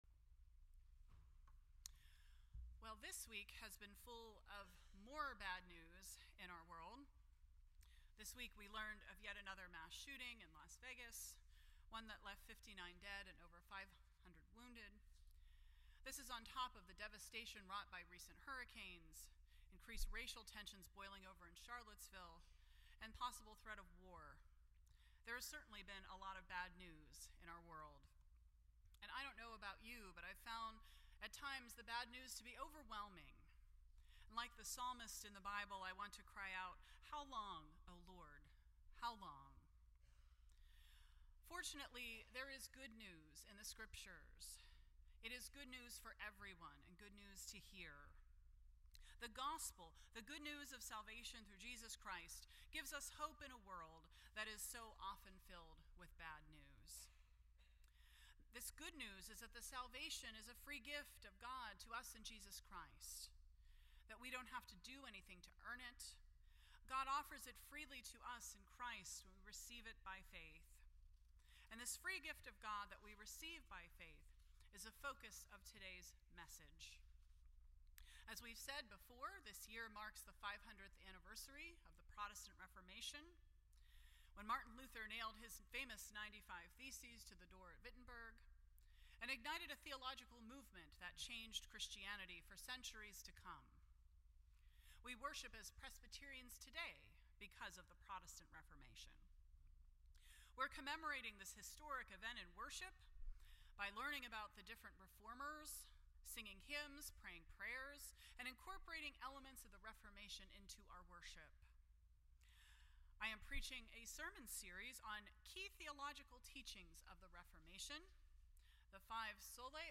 Series: The Five Solas (Onlys) of the Protestant Reformation Service Type: Sunday Morning %todo_render% Share This Story